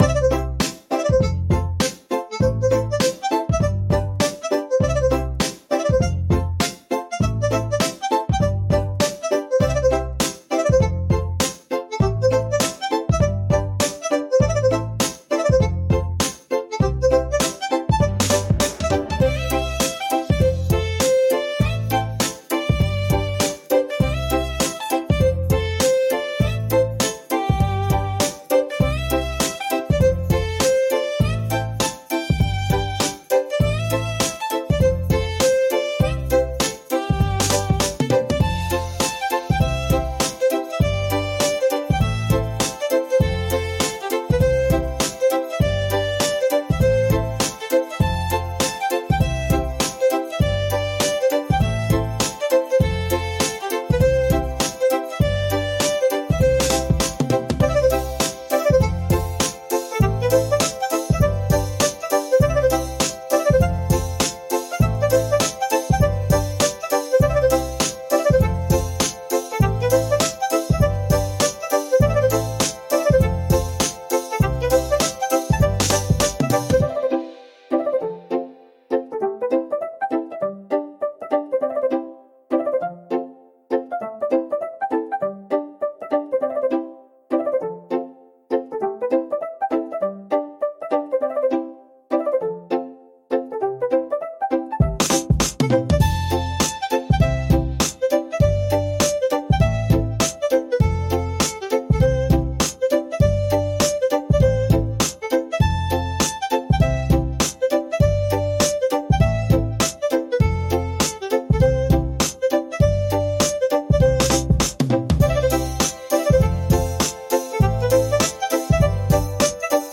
C Major – 100 BPM
Acoustic
Chill
Electronic
Pop